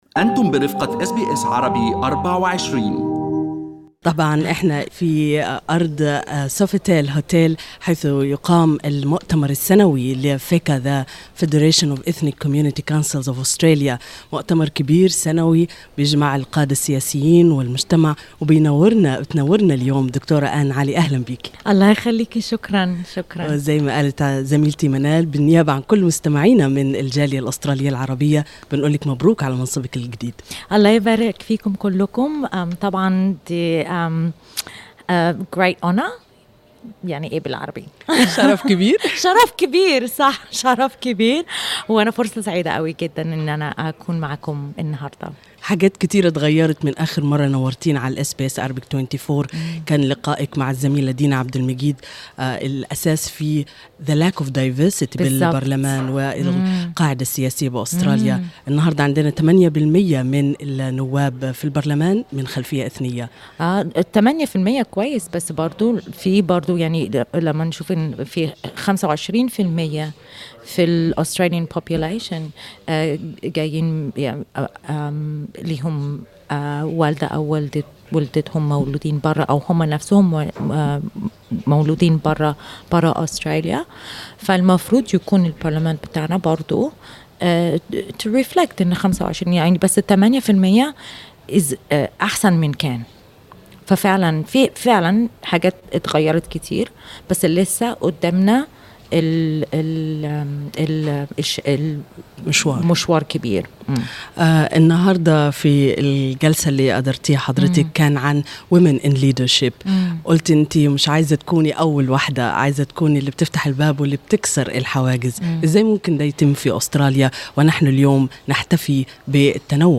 القاعة الممتلئة بالحضور كانت تنتظرها.